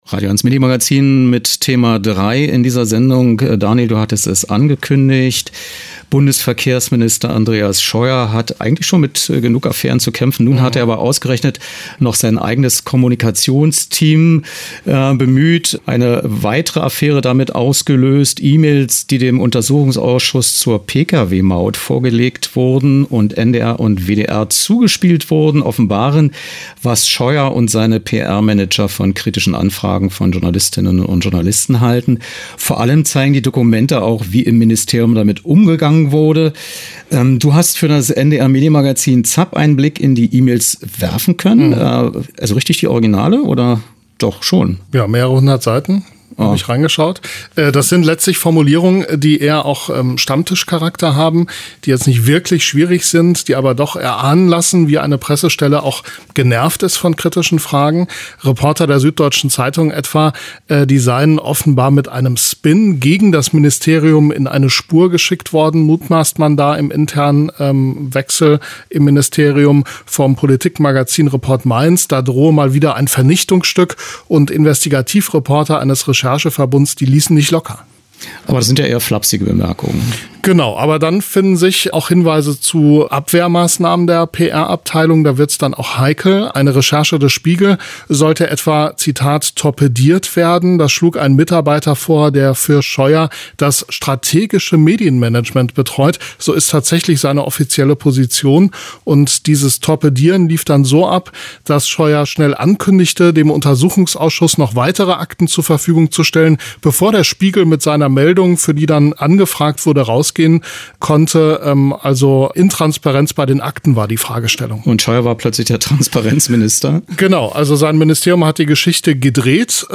* Andreas Scheuer (CSU), Bundesverkehrsminister (Quelle: Deutscher Bundestag)
Was: Studiogespräch zur neuesten Scheuer-Affäre